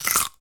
crunch05.ogg